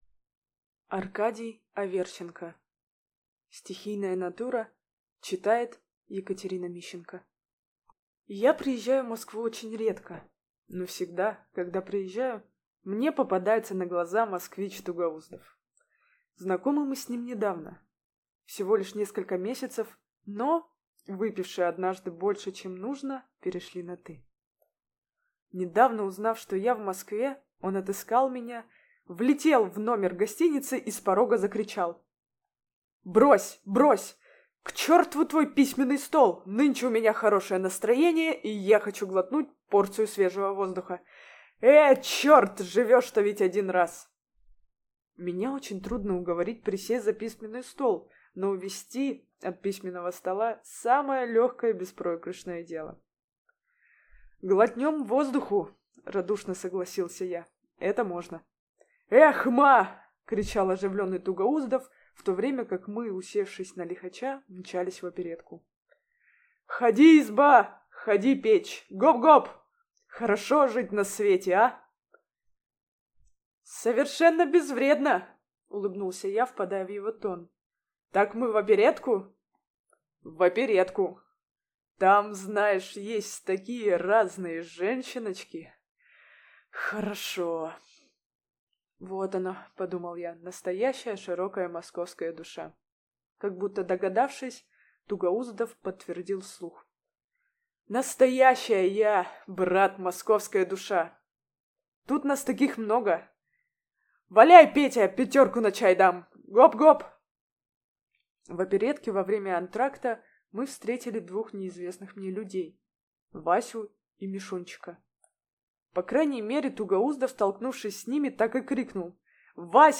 Аудиокнига Стихийная натура | Библиотека аудиокниг